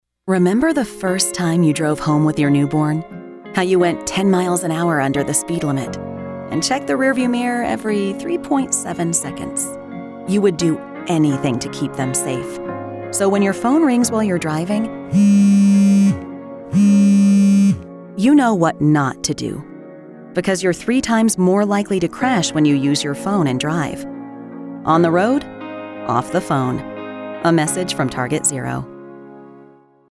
Download Distracted Driving – English Radio
WTSC-English-Radio-Spot_1final_mixdown.mp3